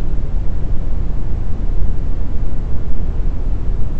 A320_aircond.wav